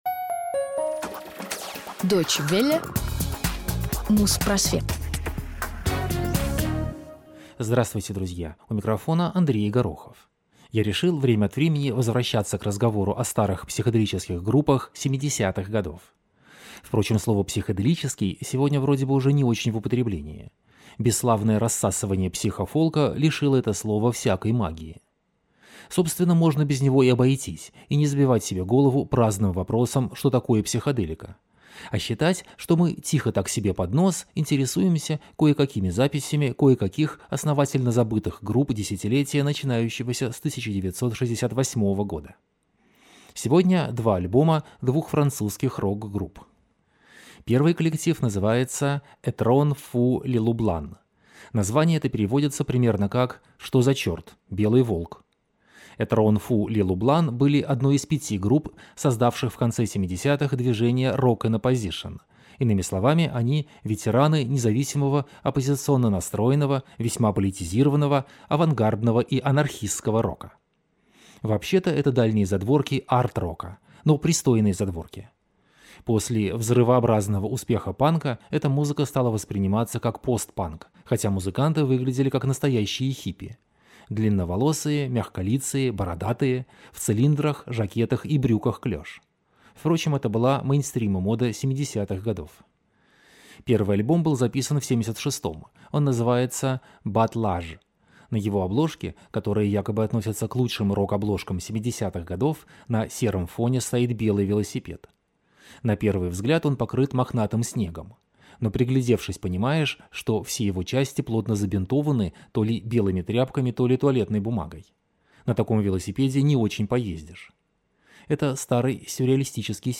Что за чёрт, баррикада! (французский андеграундный рок 70х